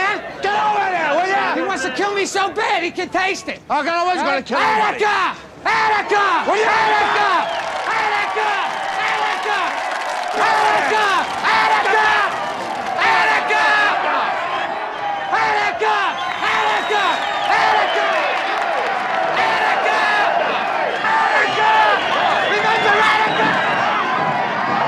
Je relance avec un extrait sans doublure (malheureusement je n'ai pas trouvé la VF)
Pour les autres, un immense acteur qui a la réputation de beaucoup crier et gesticuler :-D